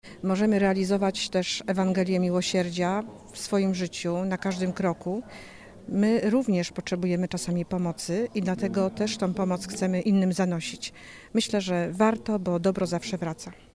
W sobotę o godzinie 10:00 rozpoczął się Zjazd Parafialnych Zespołów Caritas Archidiecezji Wrocławskiej.